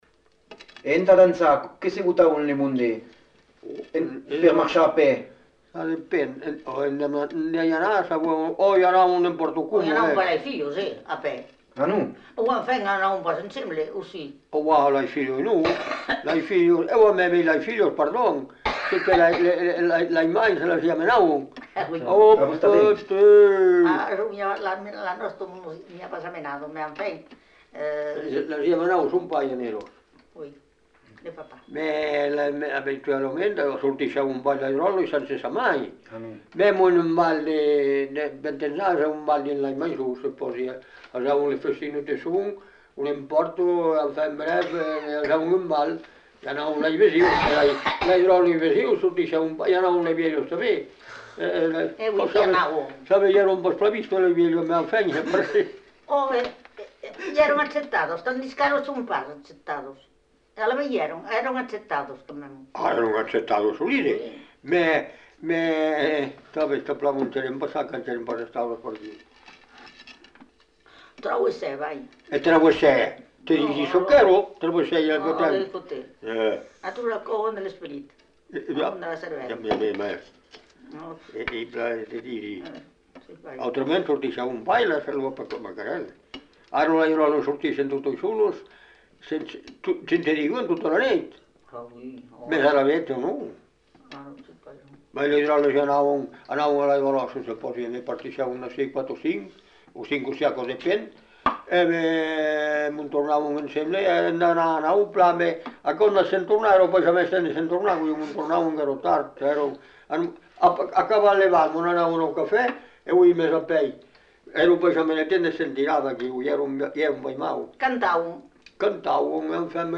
Lieu : Cazaux-Savès
Genre : témoignage thématique Descripteurs : pratique de la danse ; bal ; jeunesse ; Marestaing ; Isle-Jourdain (L') ; Cazaux-Savès ; interdit de la danse ; musicien